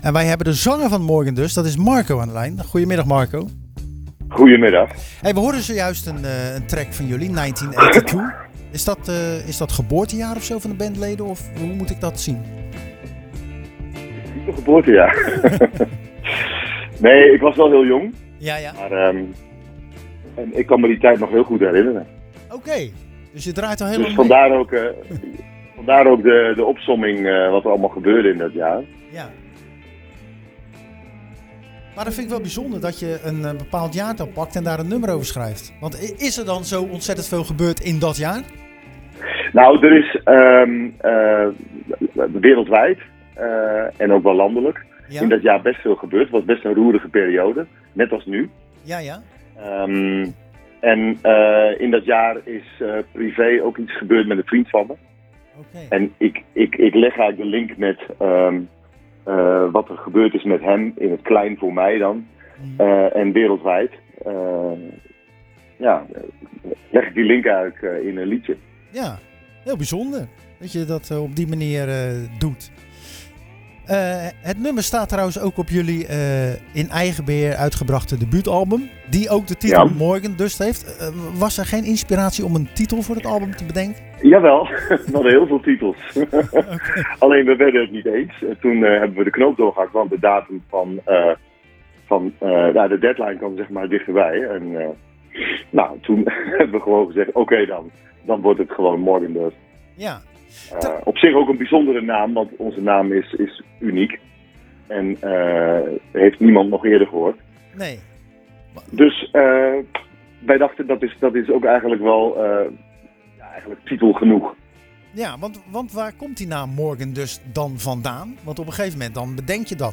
Tijdens het programma Zwaardvis belde we met zanger